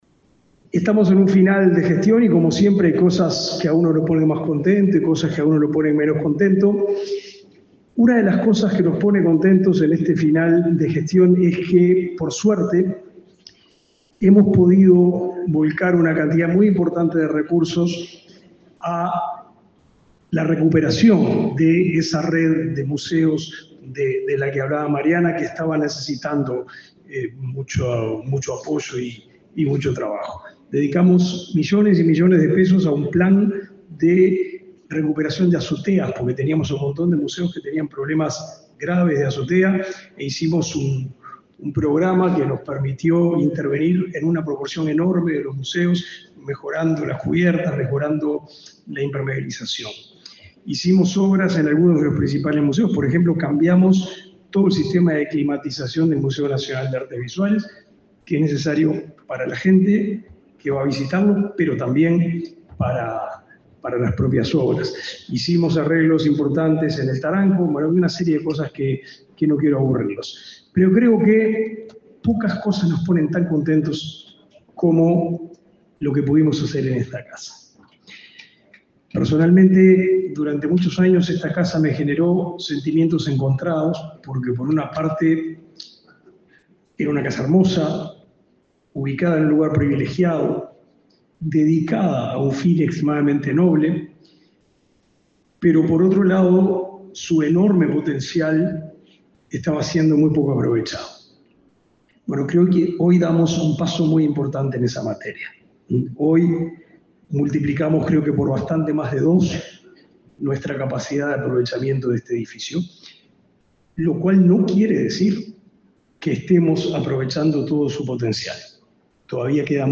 Palabras del ministro de Educación y Cultura, Pablo da Silveira
El ministro de Educación y Cultura, Pablo da Silveira, participó, este viernes 13 en Montevideo, de una nueva edición de Museos en la Noche.